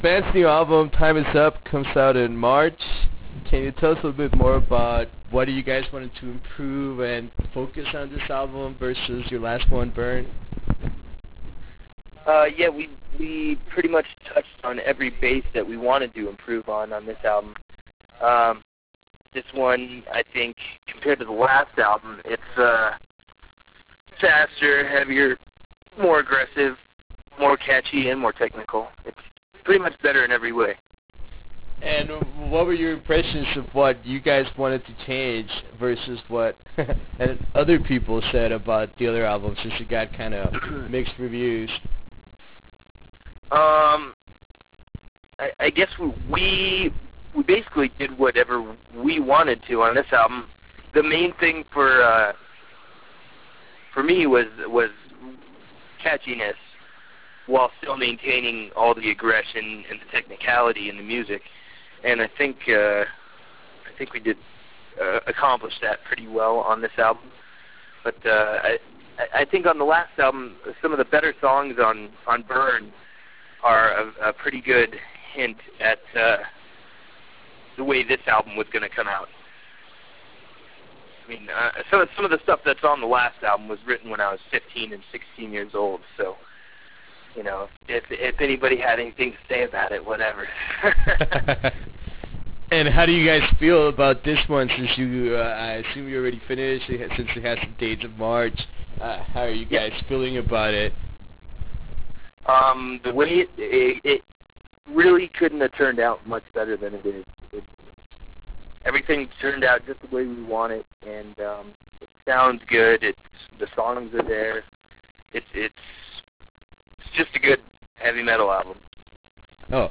Interview with Havok